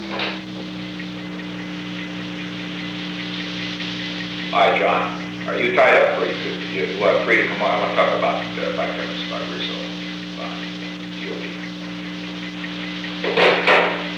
The Old Executive Office Building taping system captured this recording, which is known as Conversation 317-003 of the White House Tapes.
The President talked with John D. Ehrlichman.